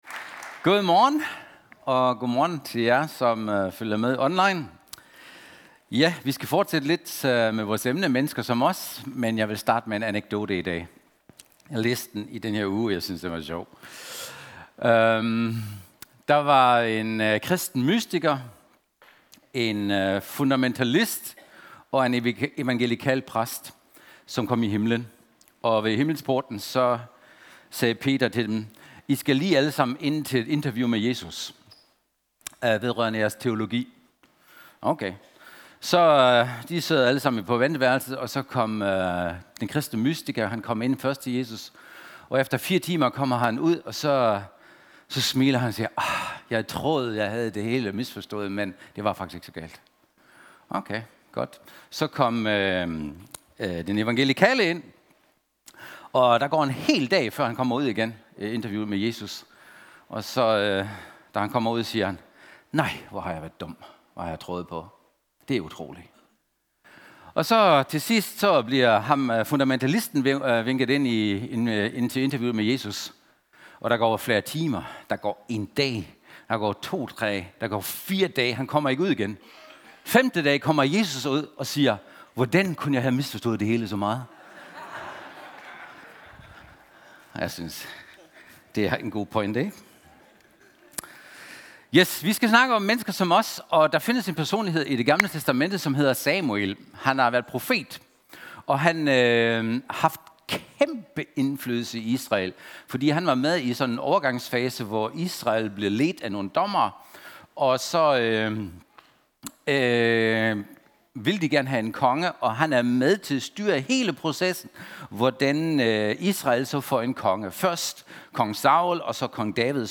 Lyt til prædikener - Citykirken Aarhus